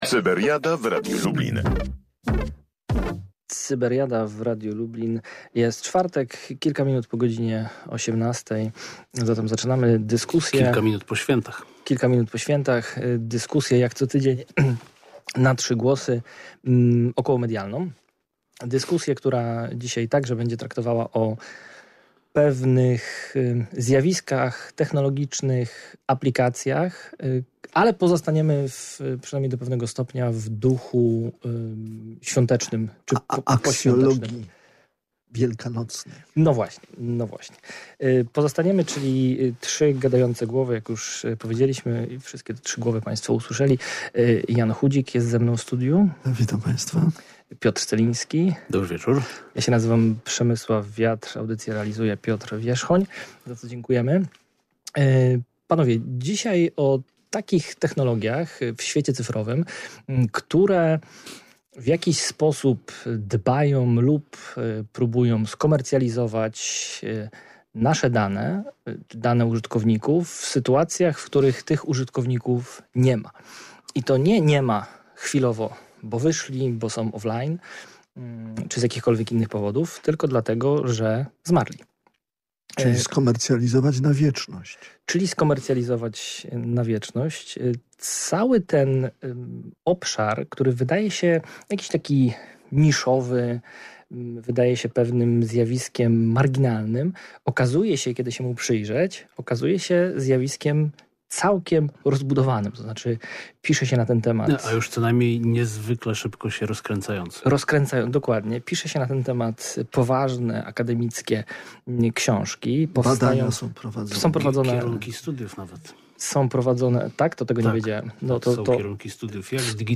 Najbliższa Cyberiada w czwartek (9.04.2026) na żywo po godzinie 18:00, tylko w Radiu Lublin (i na Spotify).